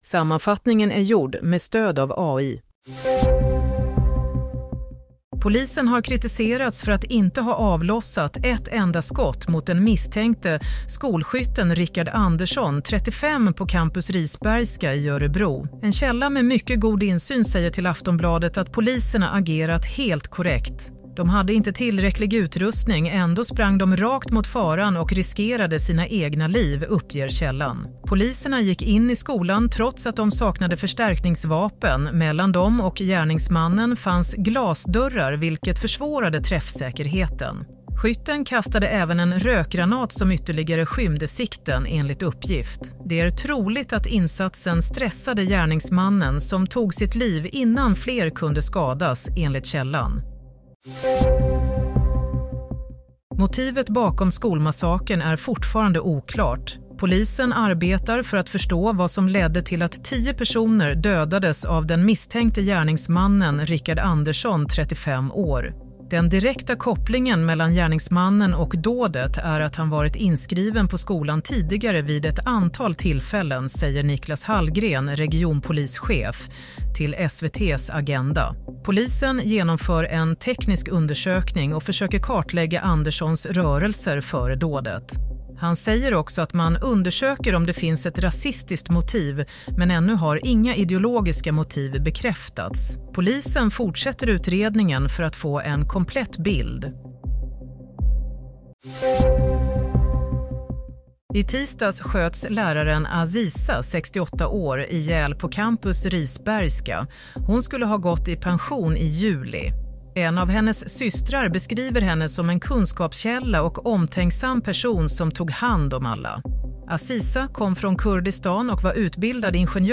Nyhetssammanfattning – 9 februari 22:00